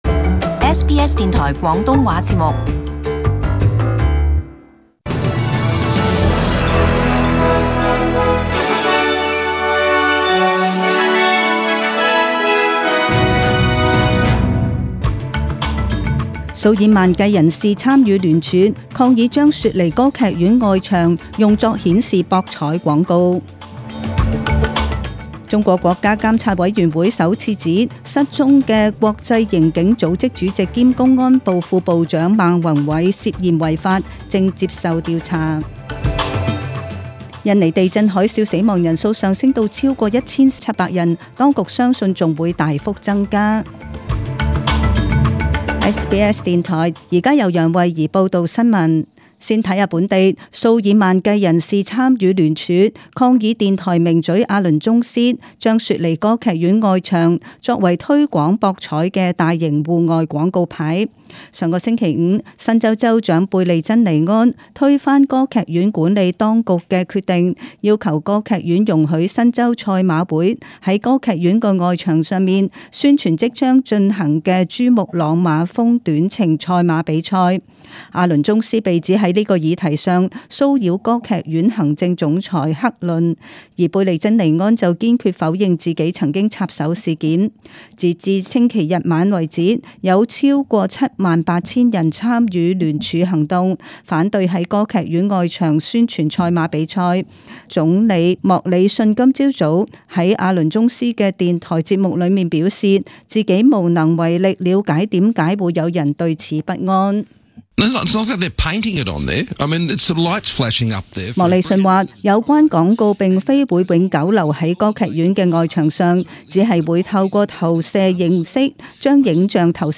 SBS中文新闻 （十月八日）
请收听本台为大家准备的详尽早晨新闻。